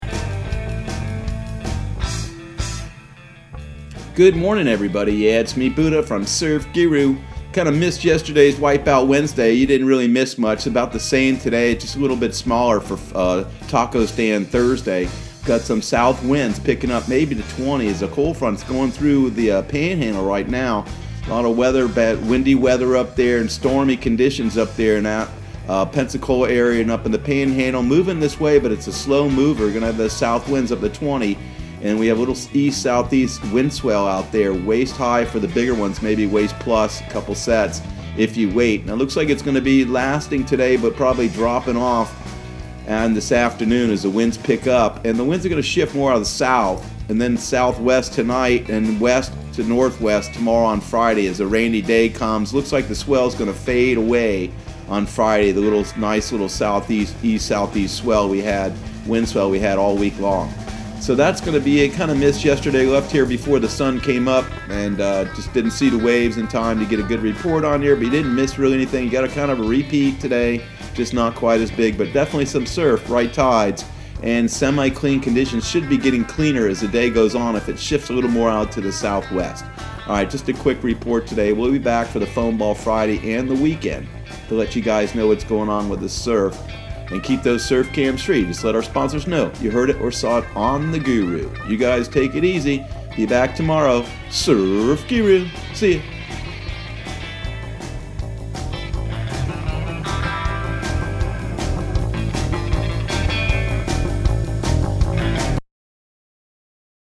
Surf Guru Surf Report and Forecast 02/13/2020 Audio surf report and surf forecast on February 13 for Central Florida and the Southeast.